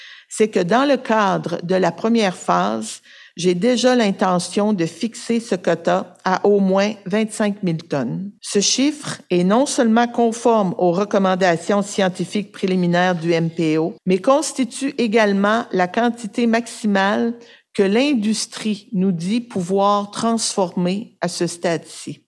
Lors de la conférence de presse qui avait lieu aujourd’hui à Rivière-au-Renard, la ministre a affirmé que la réouverture de la pêche au sébaste en sera une de transition :